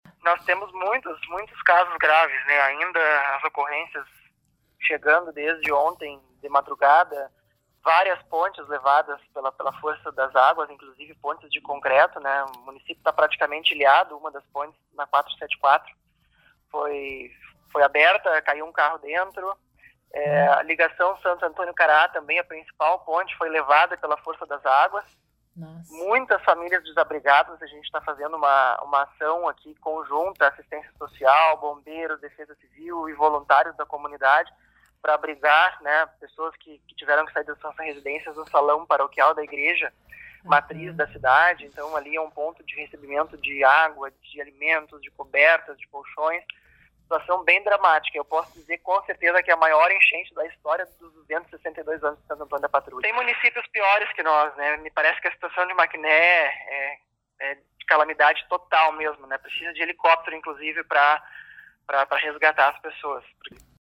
Em Santo Antônio da Patrulha, o prefeito Rodrigo Massulo relata que o município está praticamente ilhado, inclusive com um carro que entrou na cratera da ponte danificada na RS 474, que liga a cidade à FreeWay.